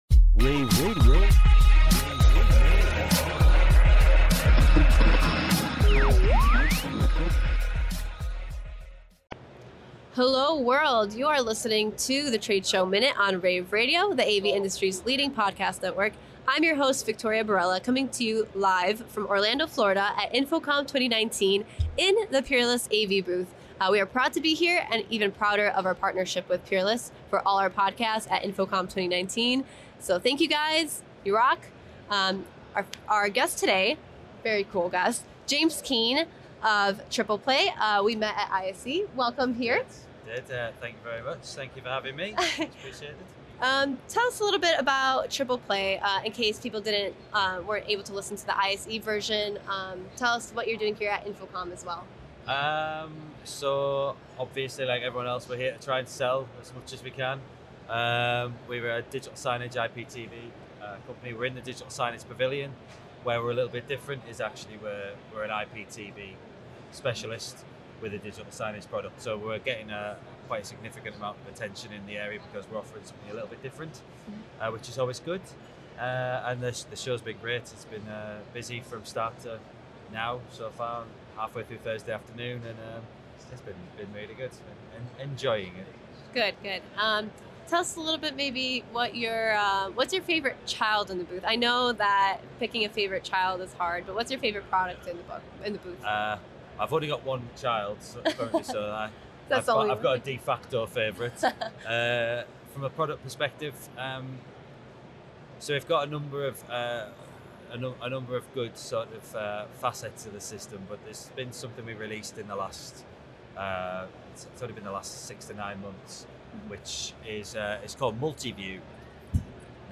June 13, 2019 - InfoComm, InfoComm Radio, Radio, rAVe [PUBS], The Trade Show Minute,